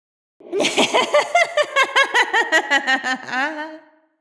Ghostly Cackle Laugh 1
ghostly_cackle_laugh_1.ogg